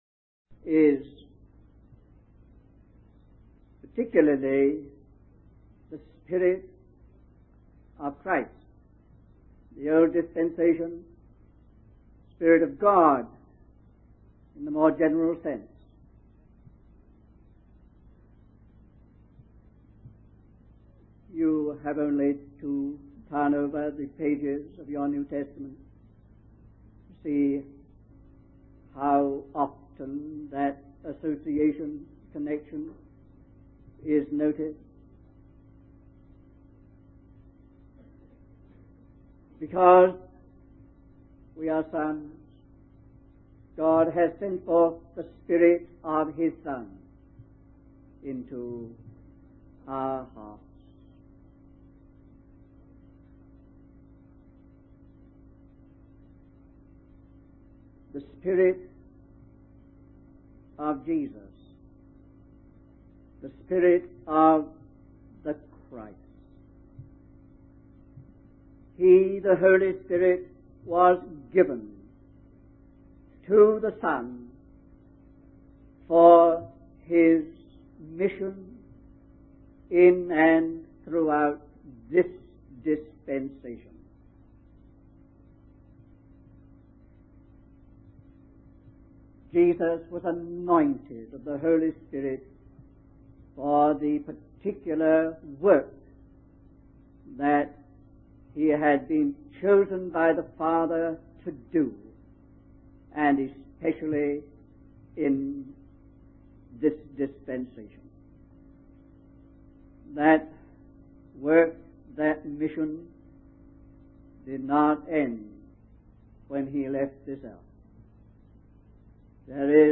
In this sermon, the preacher emphasizes the futility of human striving and the deceptive nature of the world. He argues that despite thinking they are free, humans are actually prisoners and deceived by lies.